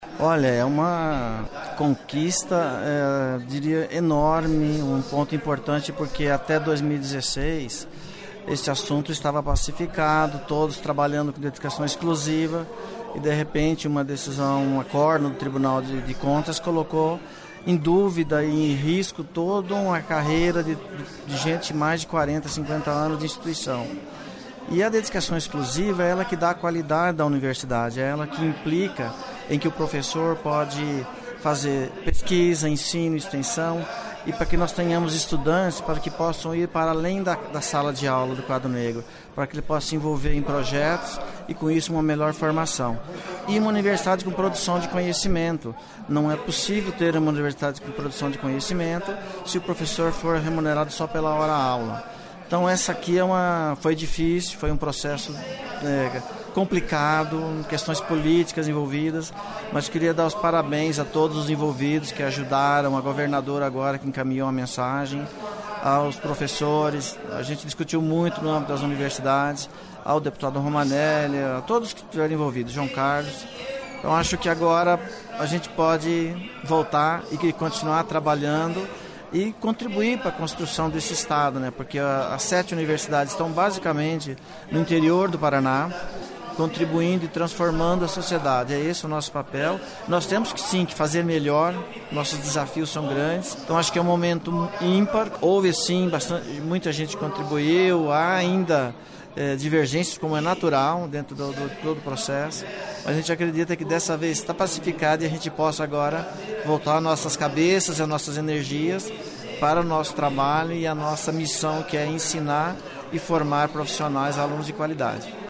Ouça entrevista do reitor da Universidade Estadual de Maringá (UEM), Mauro Baesso, onde ele celebra a votação do projeto que institui o Tempo Integral de Dedicação Exclusiva (PTIDE), como regime de trabalho, oqe beneficia professores das  sete universidades estaduais do Paraná.
(Sonora)